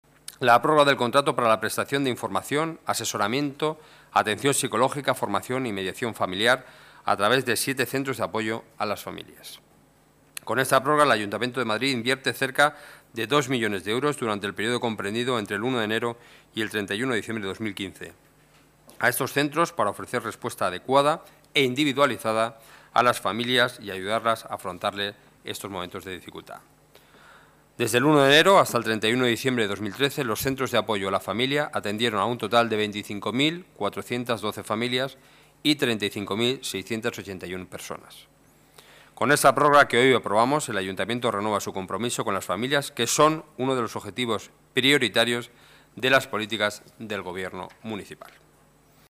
Nueva ventana:Declaraciones portavoz Gobierno municipal, Enrique Núñez: mediación familiar (CAF)